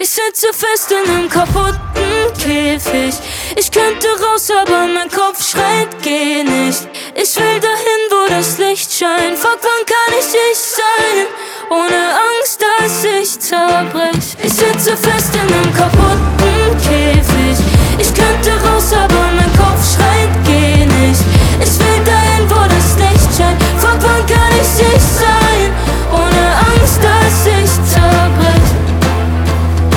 2025-02-28 Жанр: Поп музыка Длительность